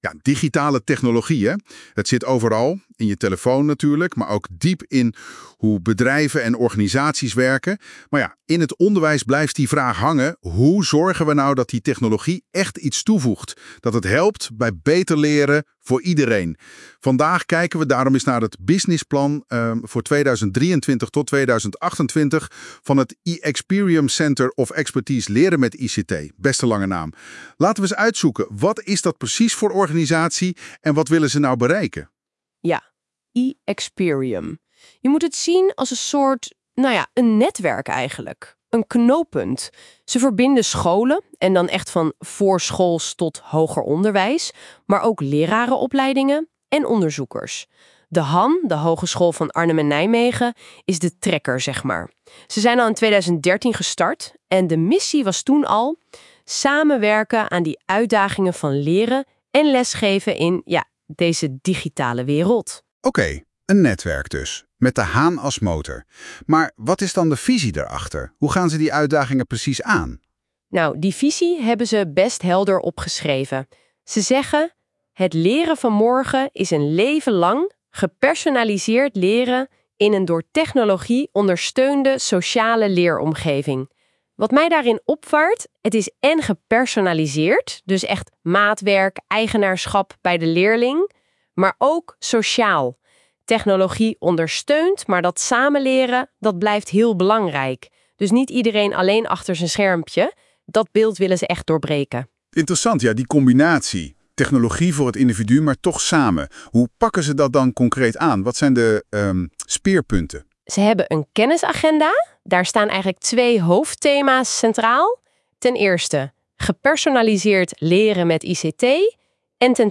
Gesprek tussen 2 personen in het Nederlands via tekst naar spraak
Die feature is bijzonder omdat het niet zomaar twee verschillende stemmen zijn die na elkaar stukjes van een verhaal vertellen, maar het lijkt alsof ze in een gesprek zijn.
Zoals je in het begin al hoort, klopt de uitspraak van iXperium niet. Het gaat één keer wel goed, maar meestal niet.